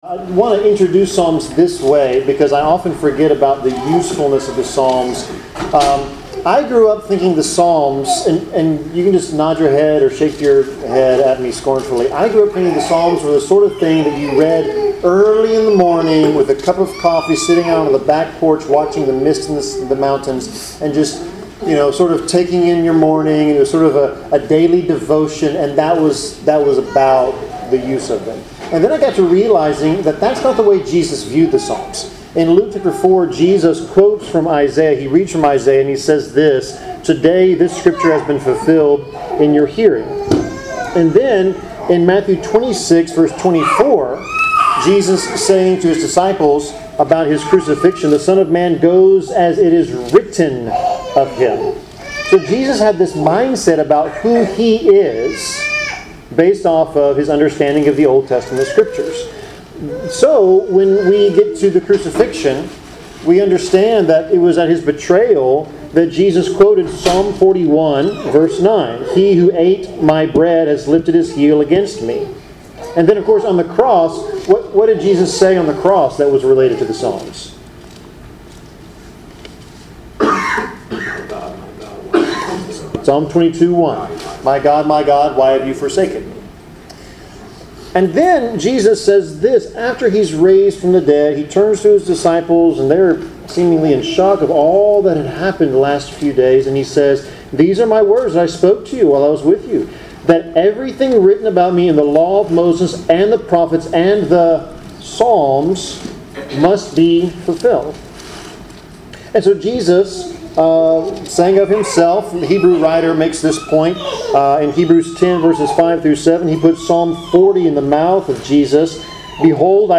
Service Type: Bible Class